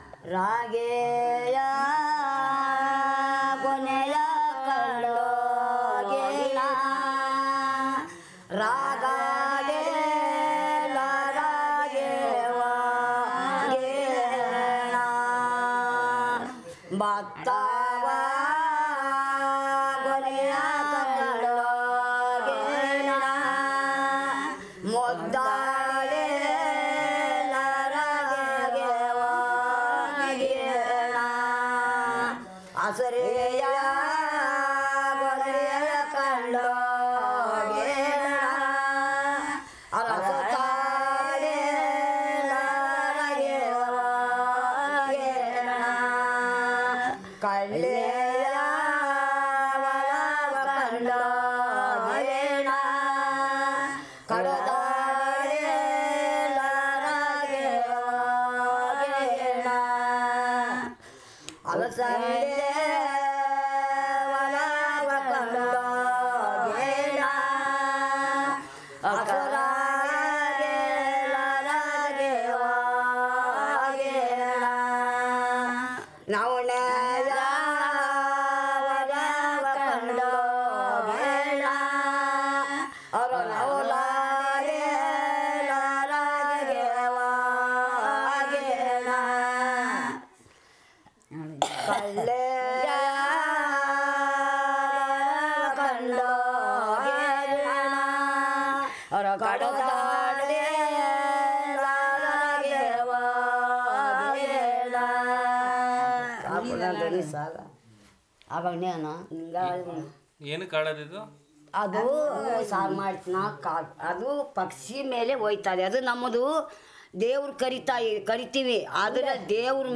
Performance of a folk song about new harvesting crop